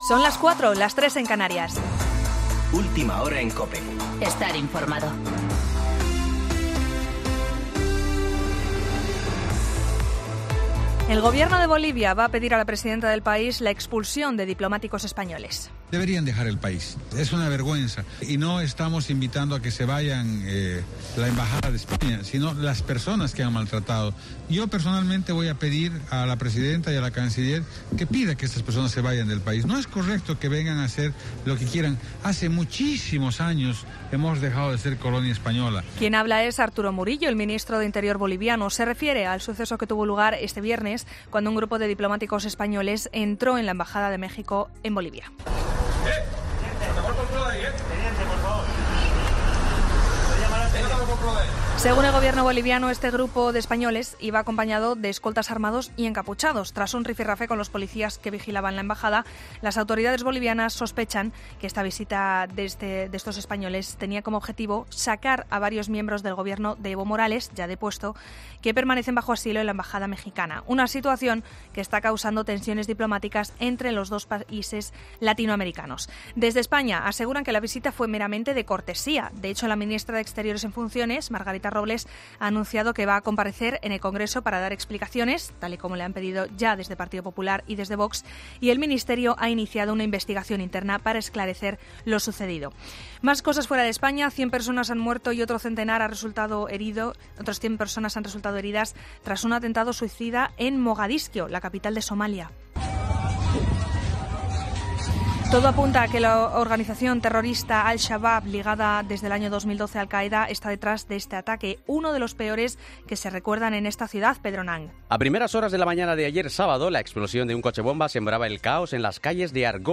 Boletín de noticias COPE del 29 de diciembre de 2019 a las 4.00 horas